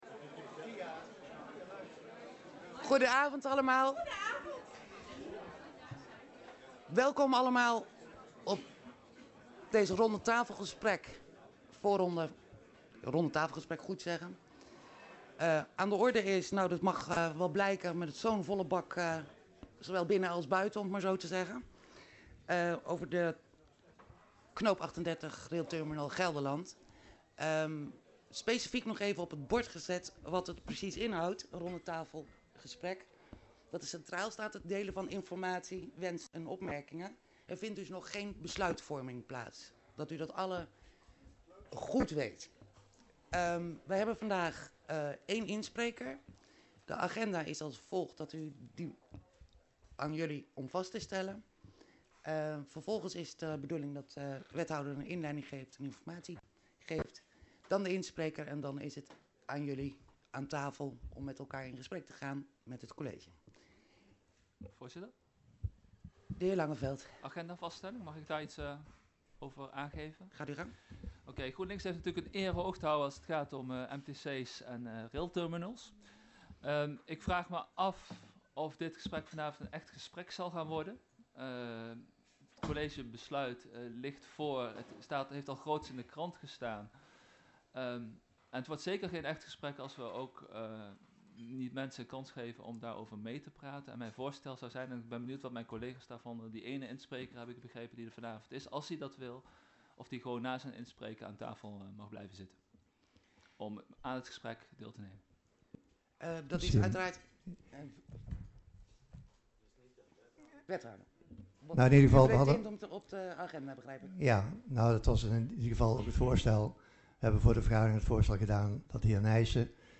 Locatie Hal, gemeentehuis Elst Voorzitter mevr. A.J. Versluis Toelichting Ronde tafel gesprek met college, voorafgegaan door presentatie en spreekrecht, over kaders Knoop 38/ Railterminal Gelderland Agenda documenten 17-09-19 Opname Voorronde Hal Ronde tafel gesprek over kaders Knoop 38-Railterminal Gelderland.MP3 46 MB